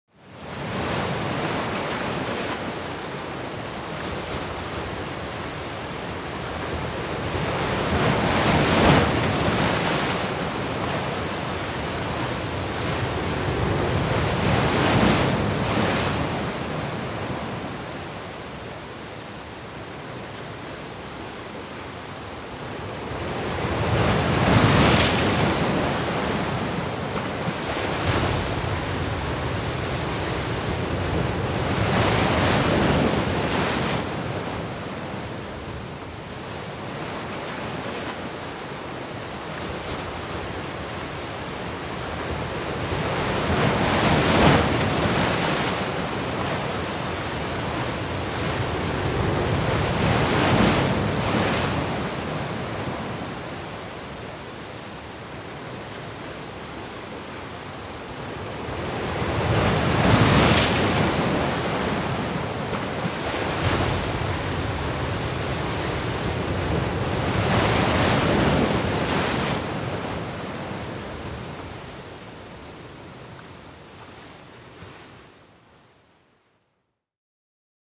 دانلود آهنگ وال 25 از افکت صوتی انسان و موجودات زنده
جلوه های صوتی
برچسب: دانلود آهنگ های افکت صوتی انسان و موجودات زنده دانلود آلبوم صدای حیوانات آبی از افکت صوتی انسان و موجودات زنده